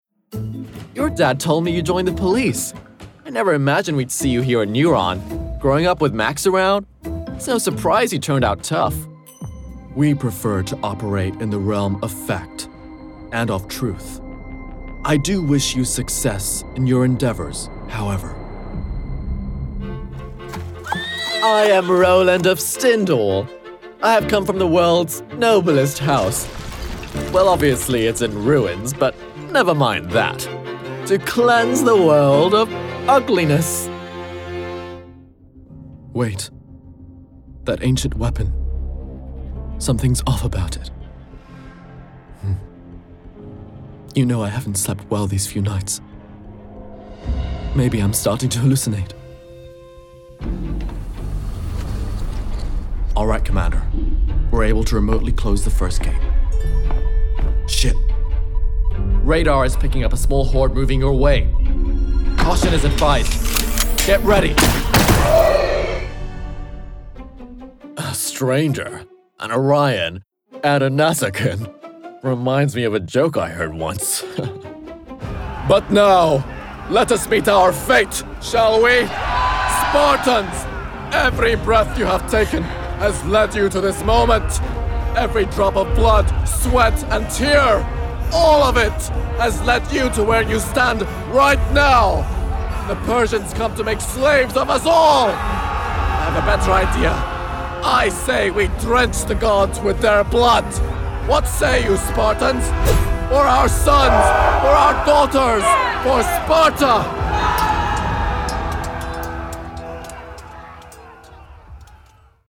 Character reel
Acting, Emotions, Versatile, Energetic, Diverse
American, RP ('Received Pronunciation'), Russian